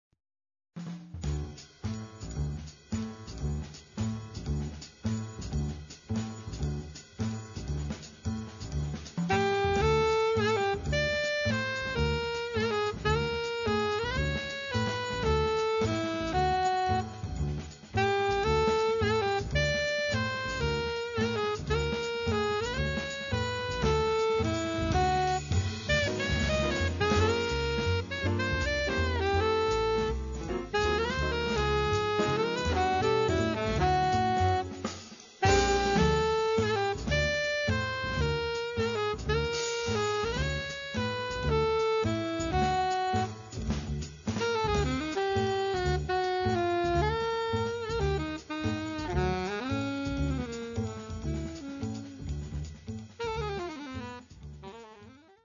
alto and sopranino saxophones
piano
bass
drums